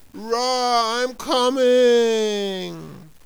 warrior_die2.wav